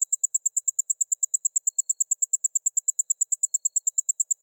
insectday_16.ogg